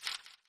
paper.opus